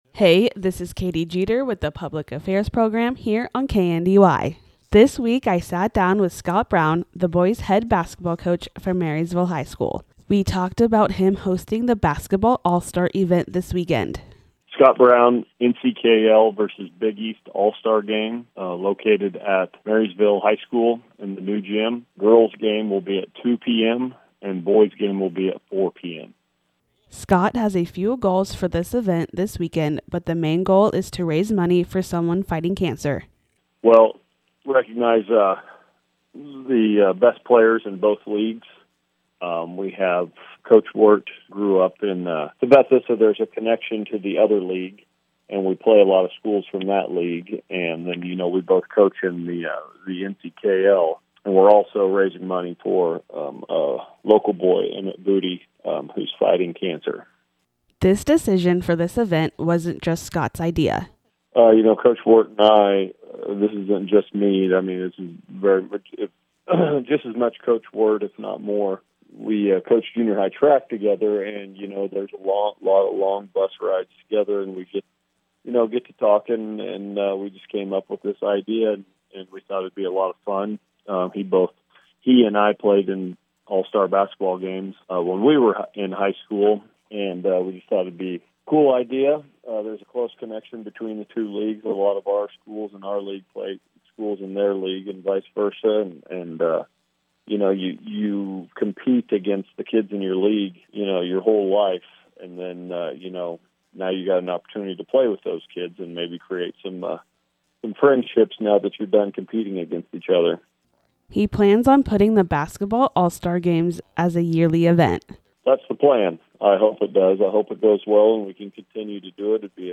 Public Affairs: Basketball All-Star Games Discussion